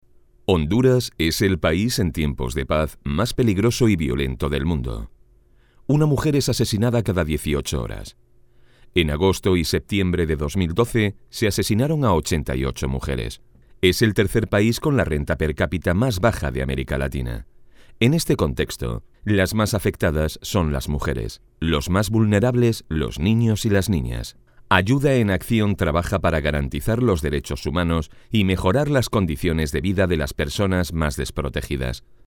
Voz directa para transmitir emociones
Sprechprobe: Industrie (Muttersprache):
Direct voice to convey emotions